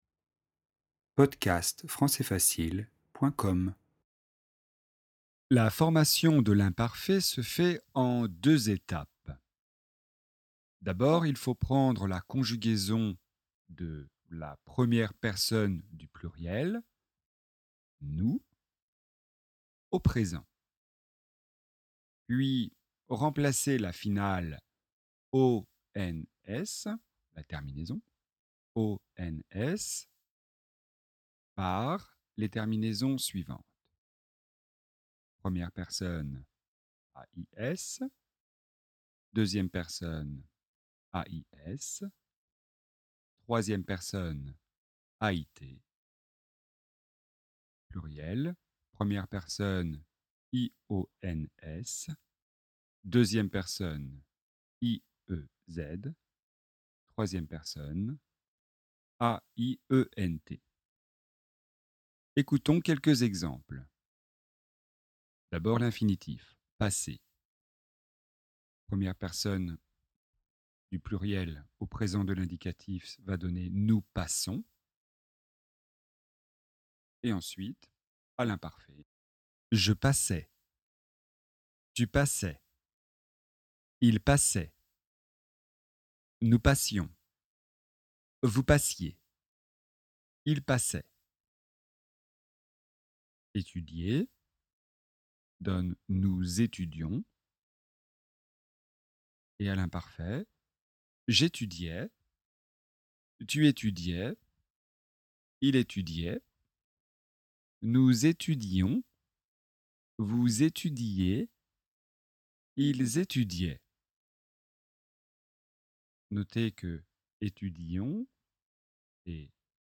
Leçon de grammaire et de conjugaison sur la formation de l'imparfait (indicatif).